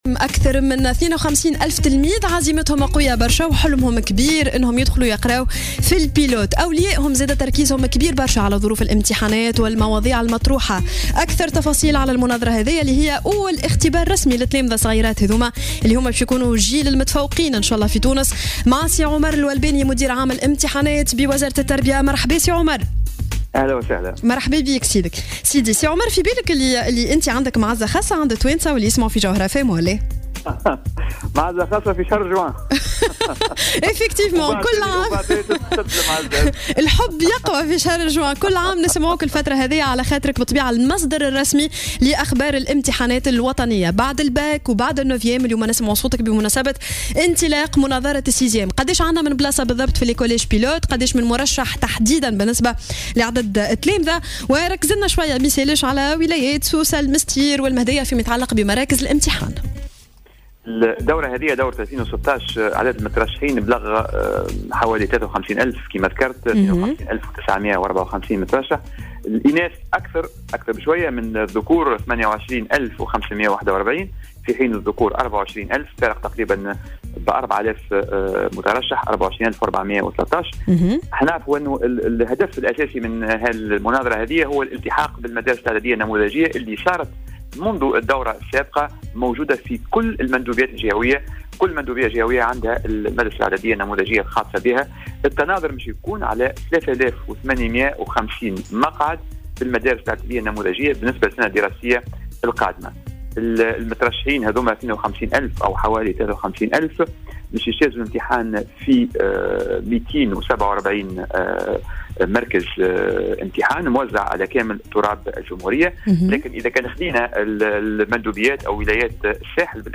وأفاد في تصريح لـ"الجوهرة أف أم" عبر برنامج "صباح الورد" أن هذه المناظرة تهدف بالأساس إلى الالتحاق بالمدارس الإعدادية النموذجية وسيتم التنافس بدءا من اليوم على 3850 مقعدا بالمدارس النموذجية.